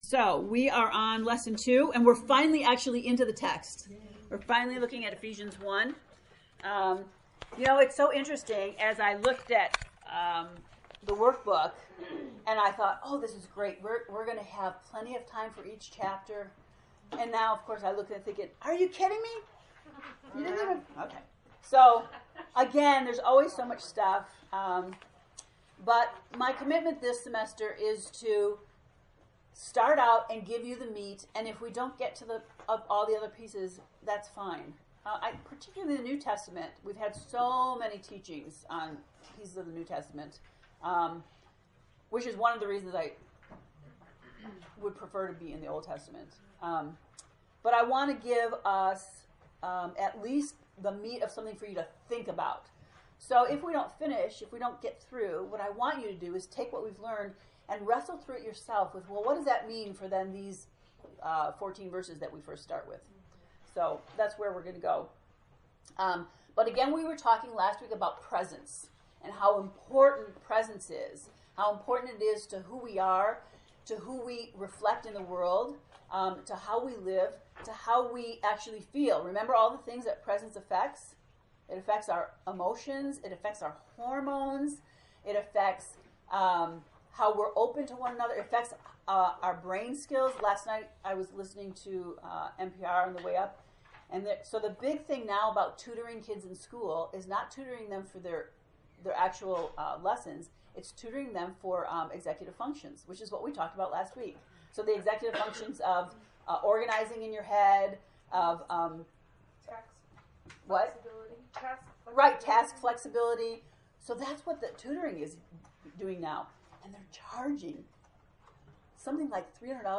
To listen to the lesson 2 lecture, “Owning Your Story”, click below: